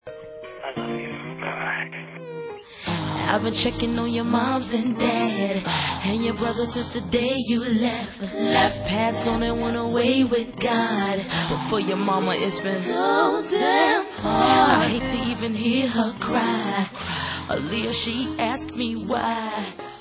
sledovat novinky v oddělení Dance/Hip Hop